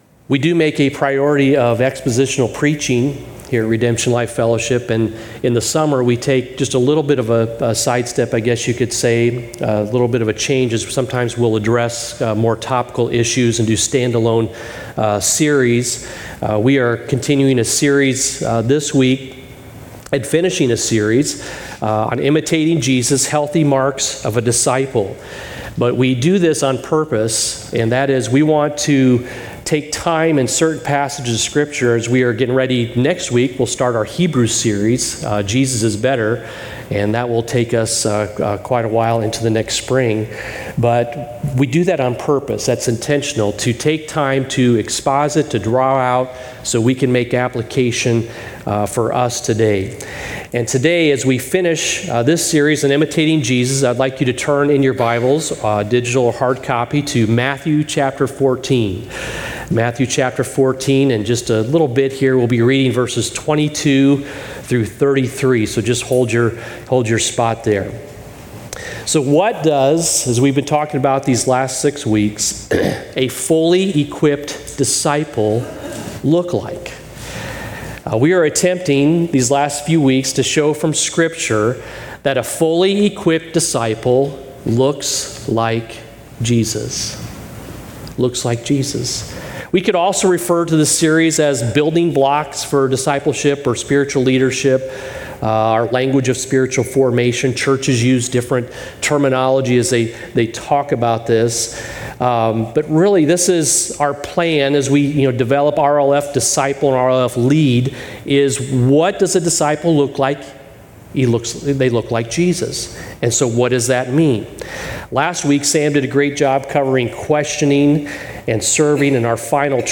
Sermon Summary: Imitating Jesus and Embracing Risk